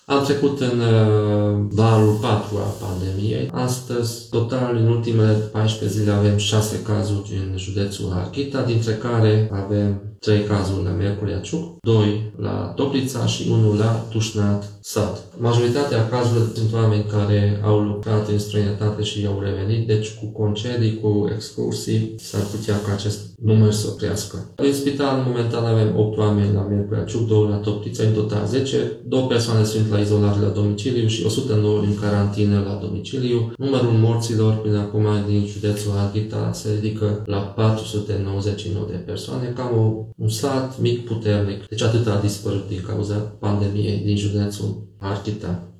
Aproape 500 de harghiteni au decedat în urma infecției cu SARS-COV2 de la începutul pandemiei, a arătat președintele Consiliului Județean, Borboly Csaba.